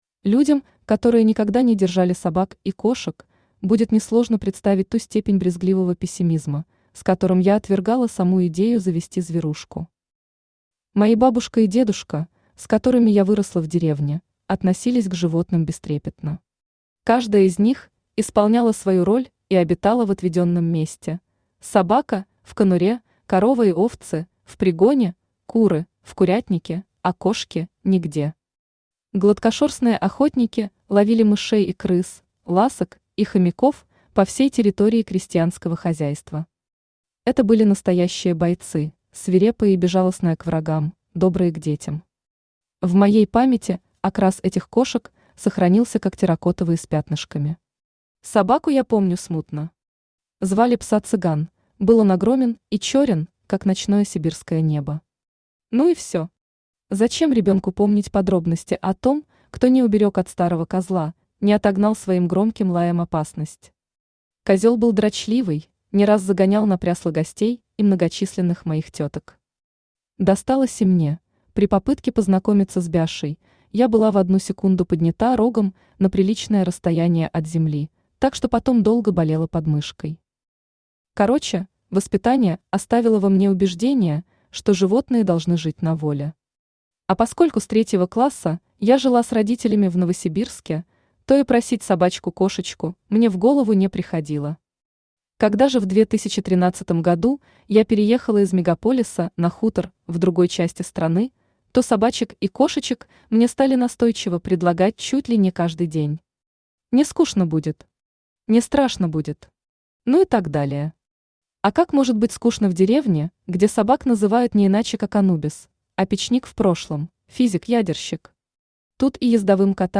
Аудиокнига Воля, Чернышевский и Калачик | Библиотека аудиокниг
Aудиокнига Воля, Чернышевский и Калачик Автор Елена Петрушина Читает аудиокнигу Авточтец ЛитРес.